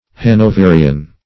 Hanoverian \Han`o*ve"ri*an\ (h[a^]n`[-o]*v[=e]"r[i^]*an), a.